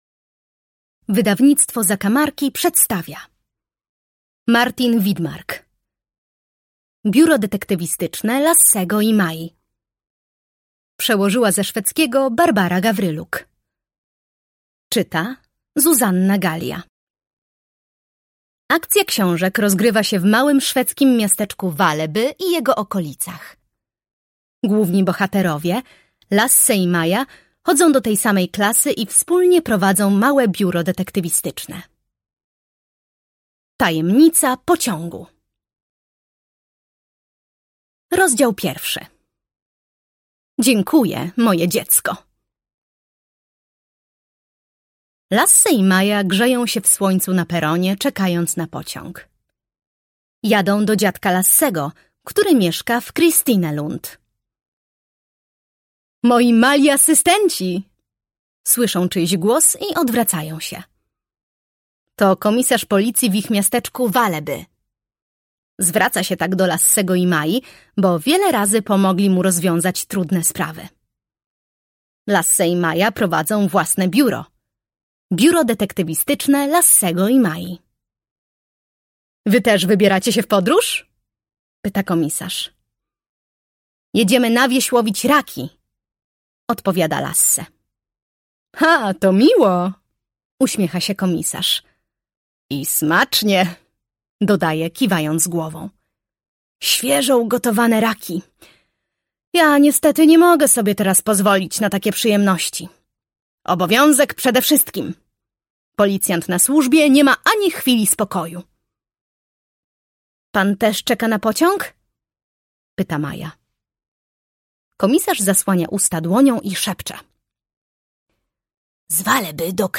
Biuro Detektywistyczne Lassego i Mai. Tajemnica pociągu - Martin Widmark - audiobook